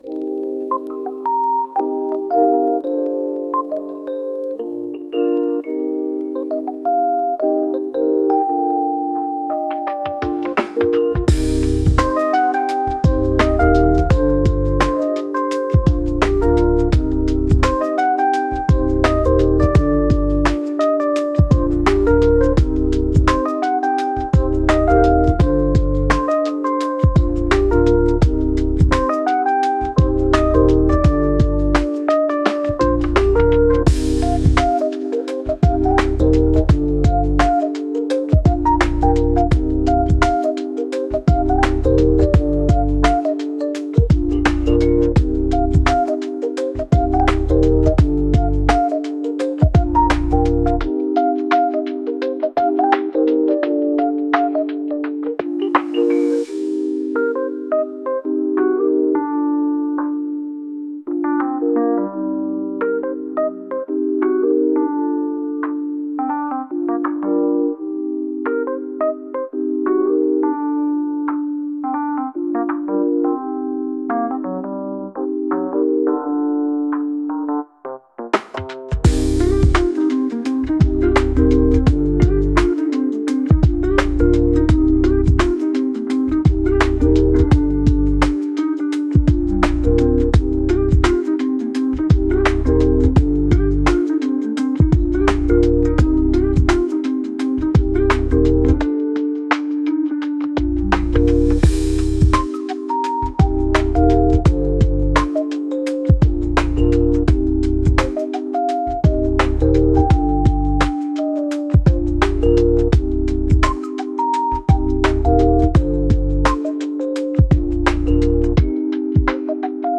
Distant whistles
Distant-whistles.wav